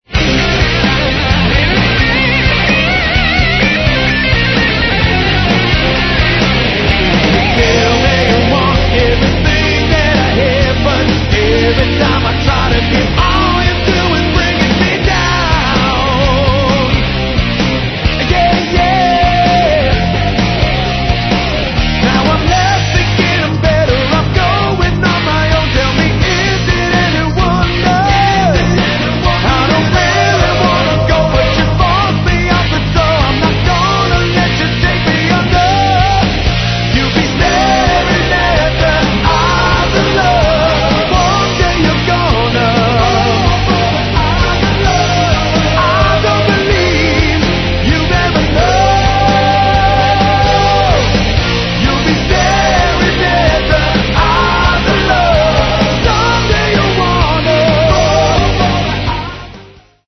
Рок
vocals
drums
lead guitars
keyboards
bass & guitars